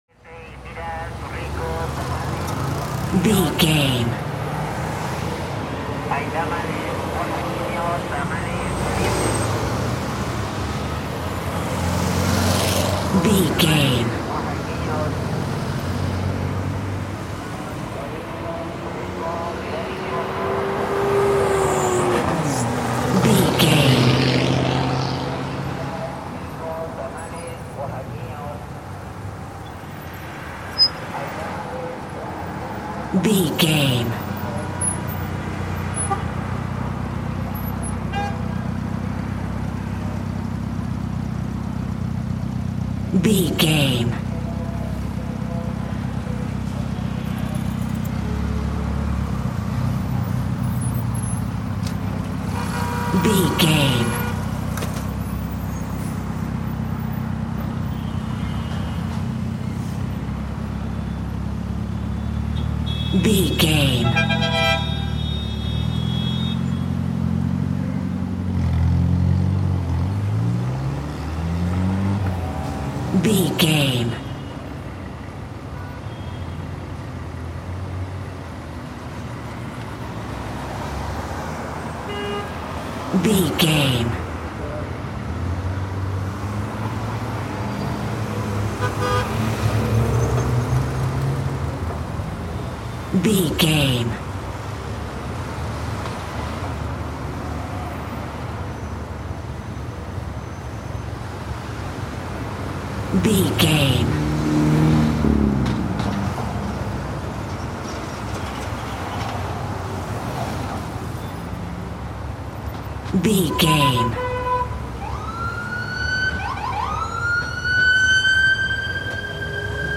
City street traffic
Sound Effects
urban
chaotic
ambience